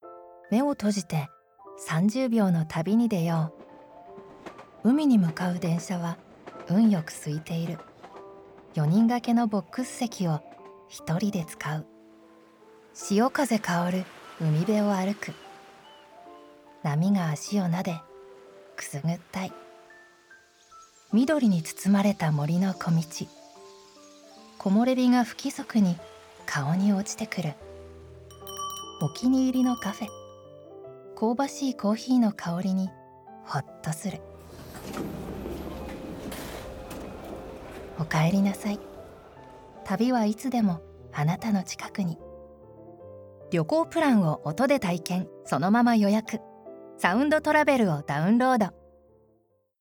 ナチュラル_つぶやき　旅アプリCM